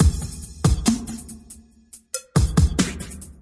70 bpm Drum Loops " Hop1
描述：用氢气制作的70bpm鼓循环
Tag: 节拍 电子